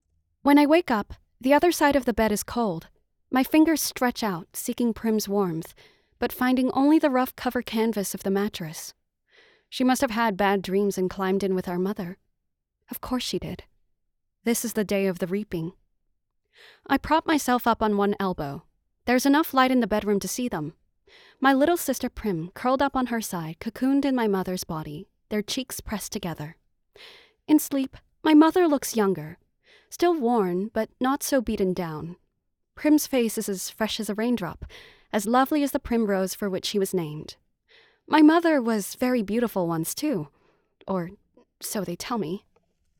standard us | character
standard us | natural